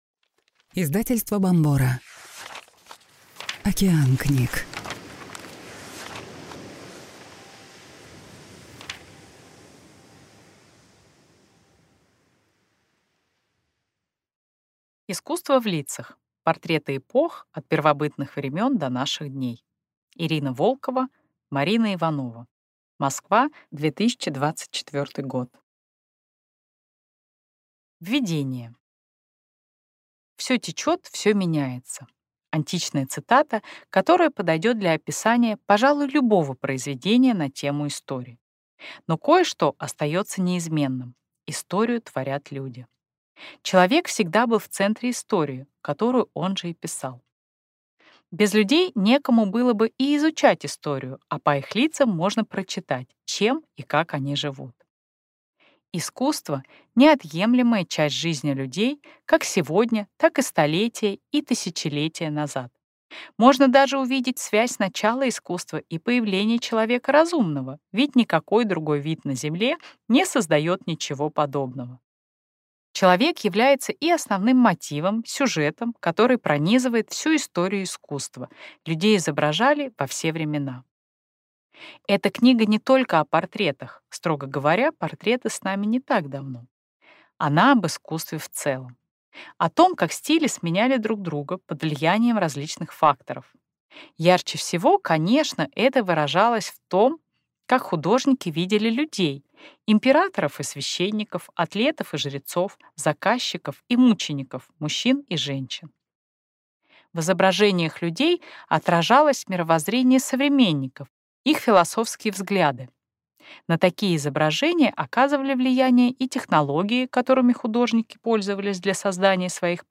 Аудиокнига «Воровка для высшего мага». Автор - Елена Горская.